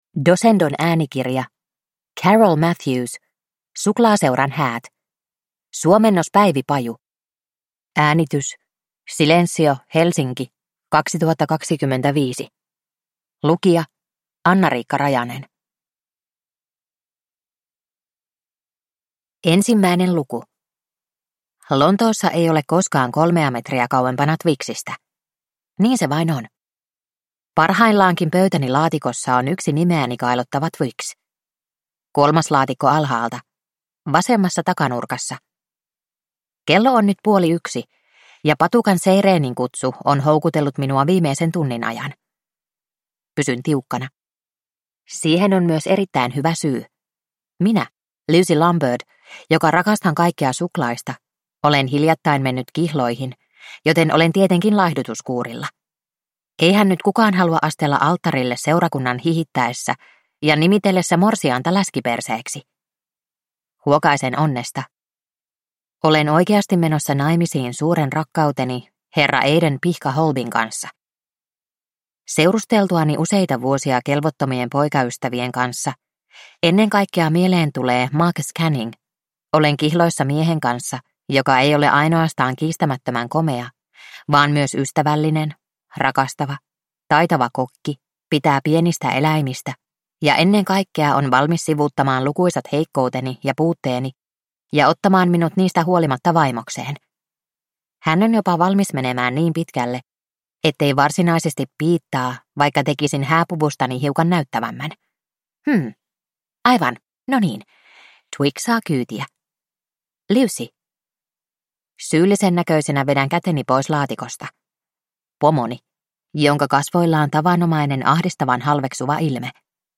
Suklaaseuran häät – Ljudbok